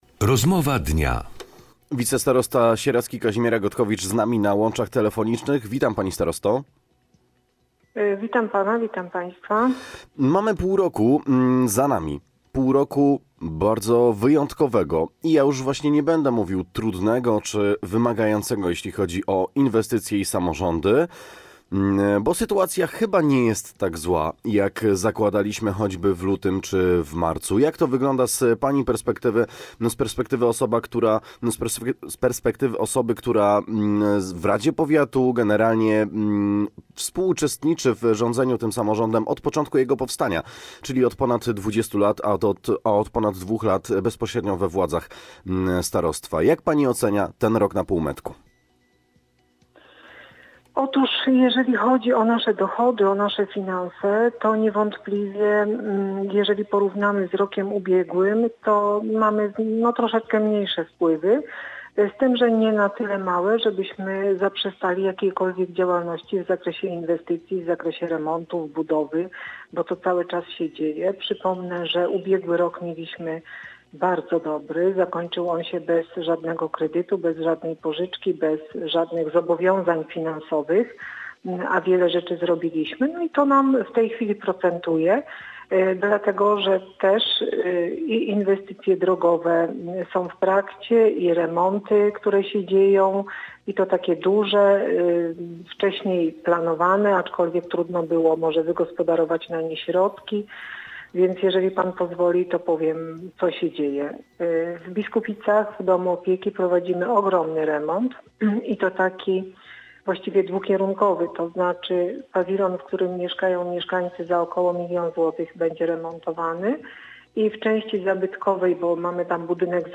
Posłuchaj całej rozmowy: Nazwa Plik Autor Rozmowa Dnia – Kazimiera Gotkowicz audio (m4a) audio (oga) Kilka miesięcy temu zastanawiano się jak poważne skutki może mieć epidemia dla samorządów.